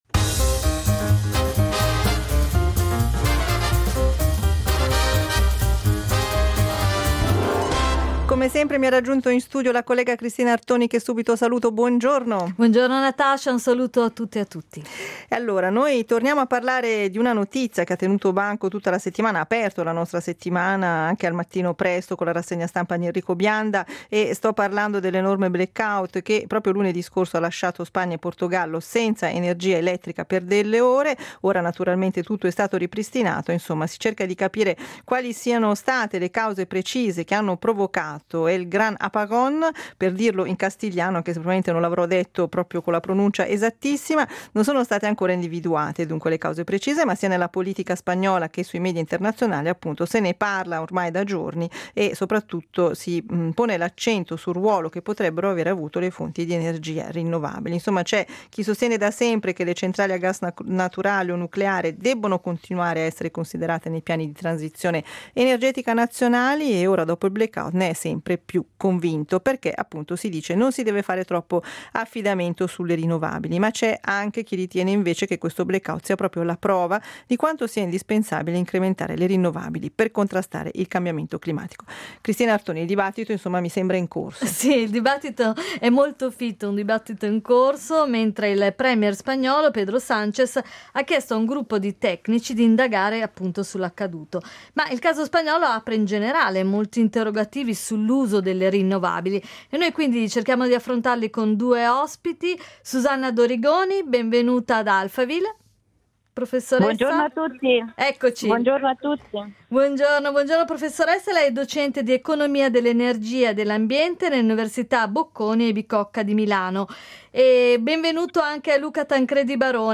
Ad Alphaville ne abbiamo parlato con due ospiti